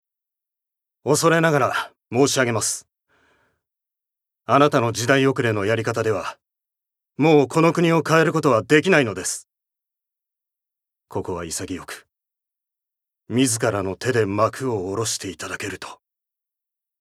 Voice Sample
ボイスサンプル
セリフ１